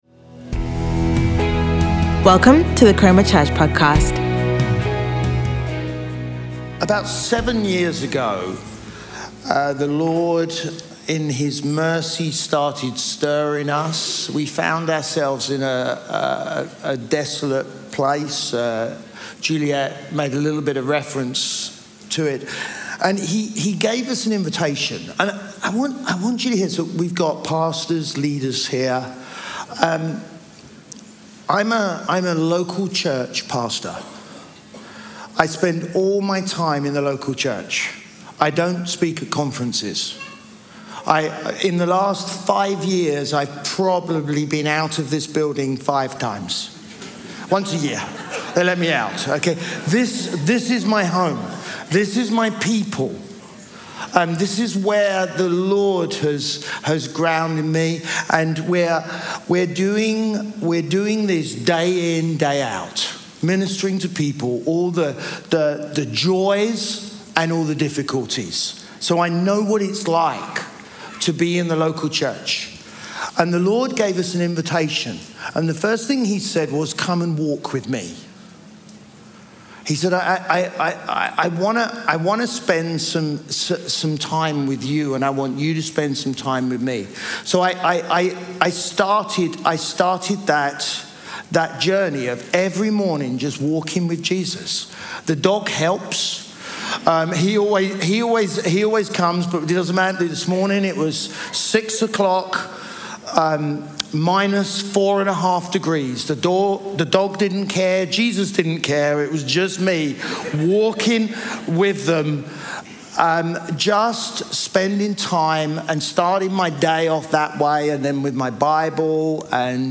Leaders Gathering